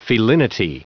Prononciation du mot felinity en anglais (fichier audio)
Prononciation du mot : felinity